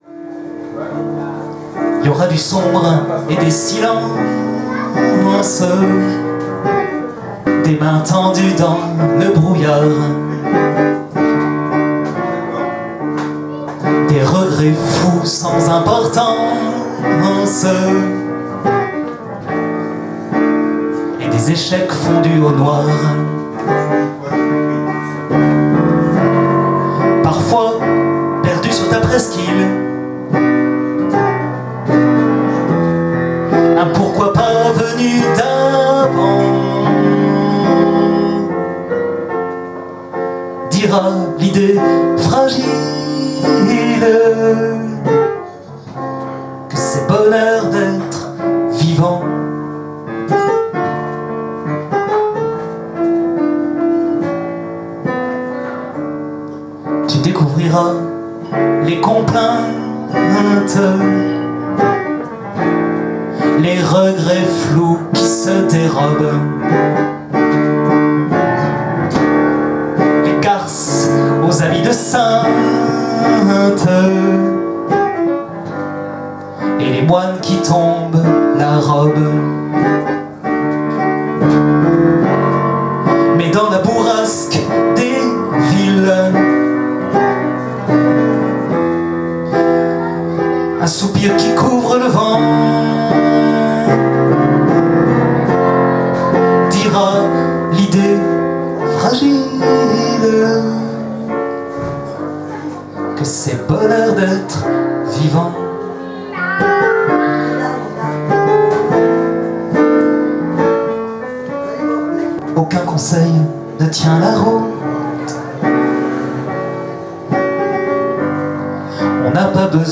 Enfin une chanson de naissance qui réunit « quenouille », « gourou » et « c’est bonheur ». L’exploit a été enregistré au Clin’s 20 le 9 juillet 2015, et je crois que, avec ces précisions super faites à Thouars, l’on a quasi fait le tour encyclopédique du sujet.